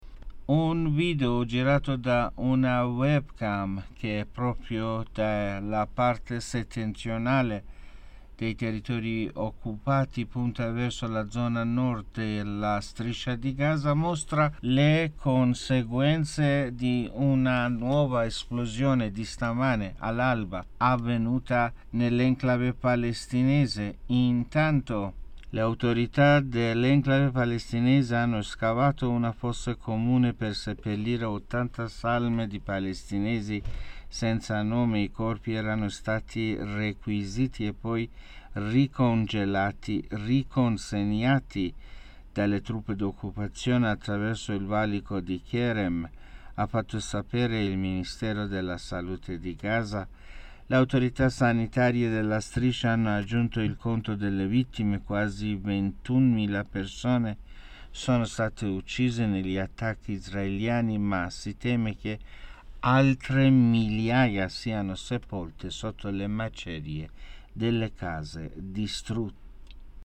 (AUDIO) Gaza, forte esplosione all'alba, nuovo attacco esercito sionista
GAZA - Un video, girato da una webcam che dal confine di Gaza dai territpri occupati punta verso il nord della Striscia, mostra le conseguenze dell'esplos...